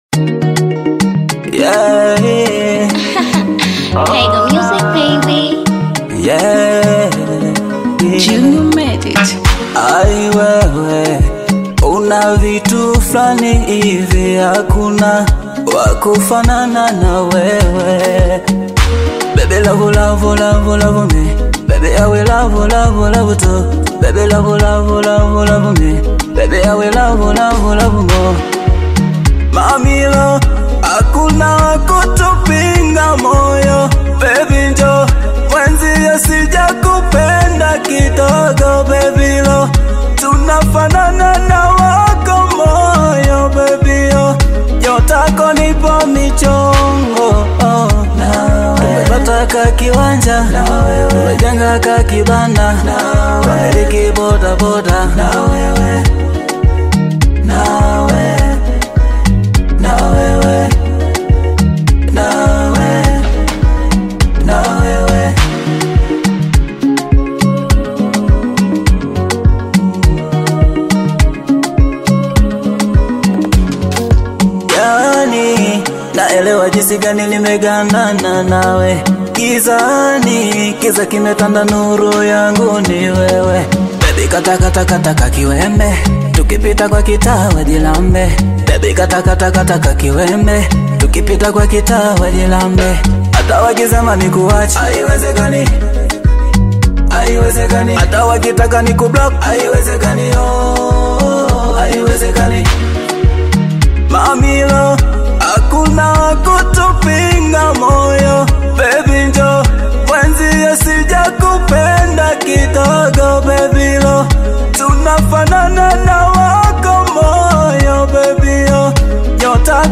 Tanzanian Bongo Fleva
is a soulful love song